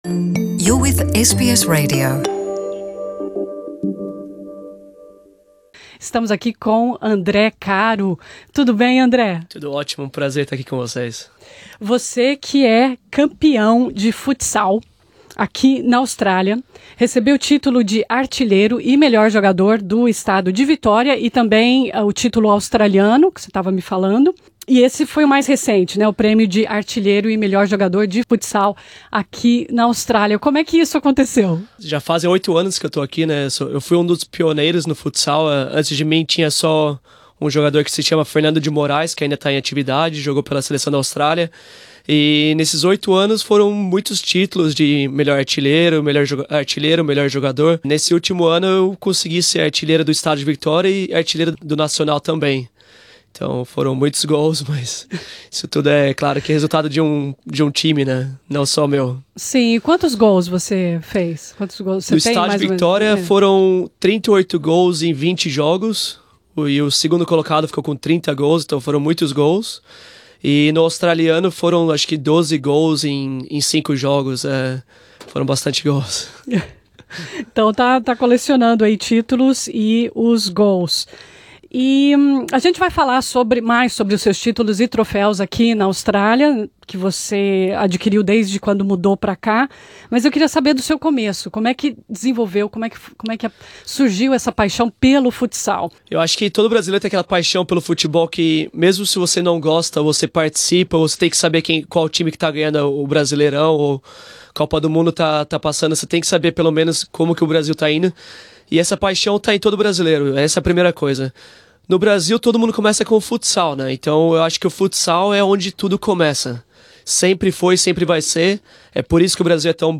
Nessa entrevista ele fala como está ajudando a popularizar o esporte na Austrália.